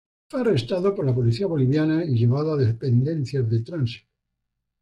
Read more (masculine) transit (masculine) traffic Frequency C1 Hyphenated as trán‧si‧to Pronounced as (IPA) /ˈtɾansito/ Etymology Inherited from Latin trānsitus In summary Borrowed from Latin trānsitus.